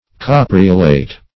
Search Result for " capreolate" : The Collaborative International Dictionary of English v.0.48: Capreolate \Cap"re*o*late\, a. [L. capreolus wild goat, tendril, fr.caper goat: cf. F. capr['e]ol['e].]